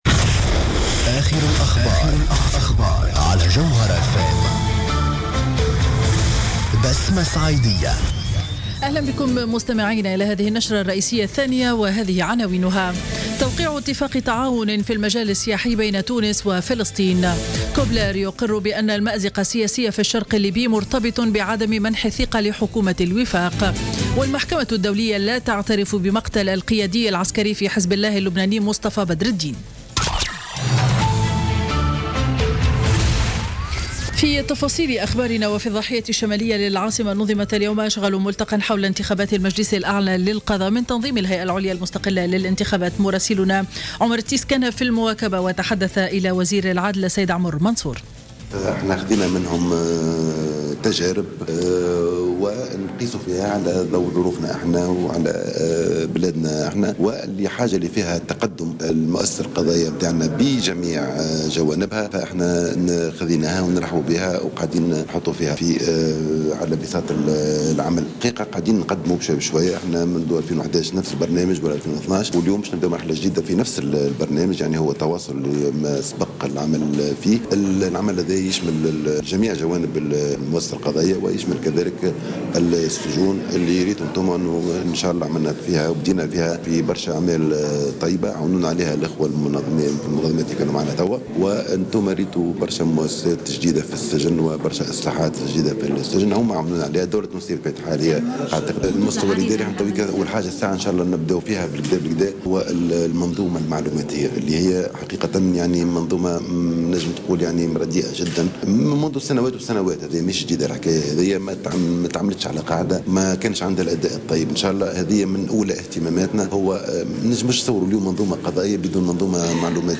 نشرة أخبار منتصف النهار ليوم الخميس 02 جوان 2016